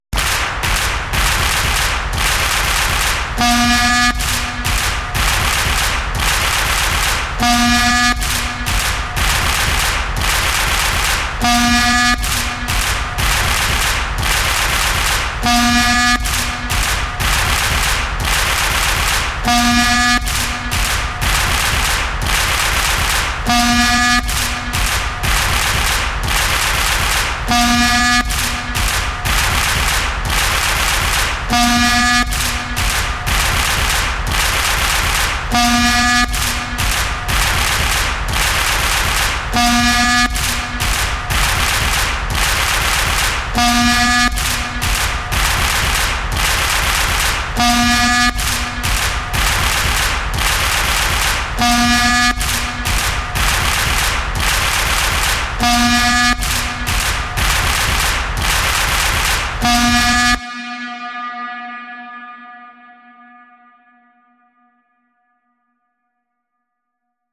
Здесь вы можете слушать и скачивать разные варианты гула: от монотонного гудения до интенсивного рева.
Звук футбольных болельщиков хлопающих в ладоши и звучащих вувузел